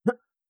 Jump1.wav